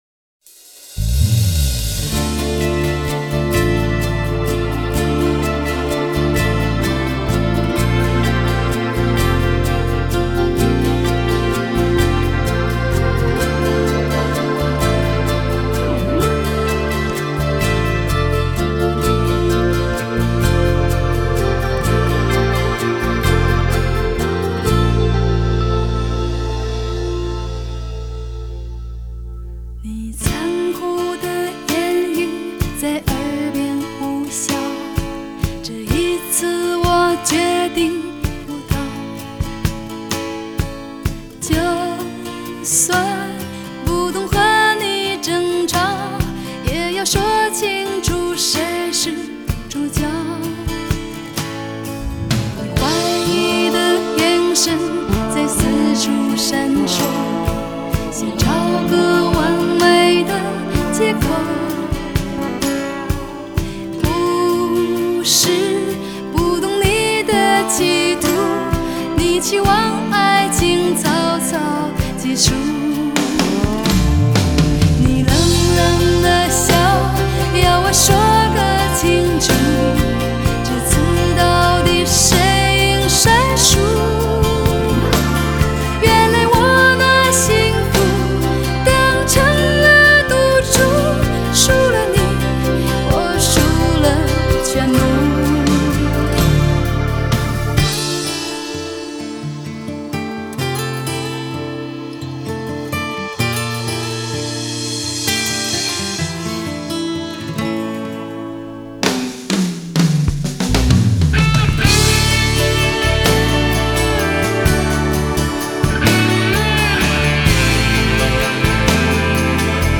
Ps：在线试听为压缩音质节选，体验无损音质请下载完整版 作词